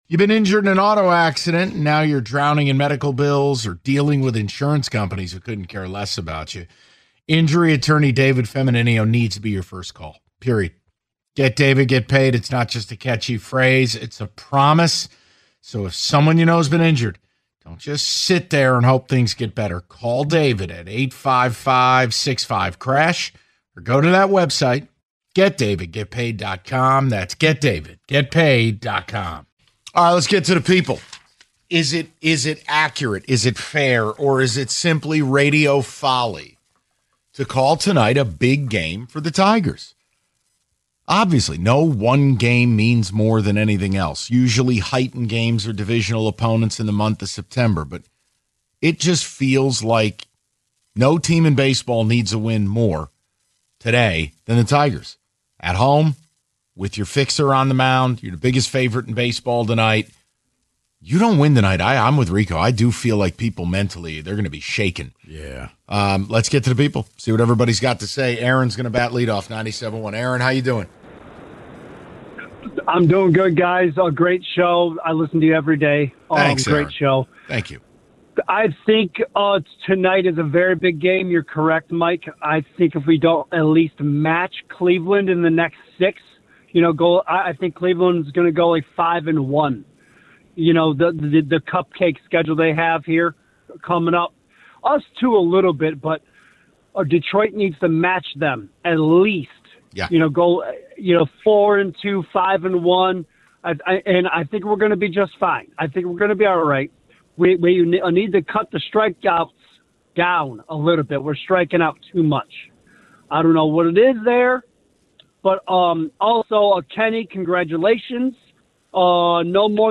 Taking Your Calls On The Potential Urgency Of Tonight's Tigers Game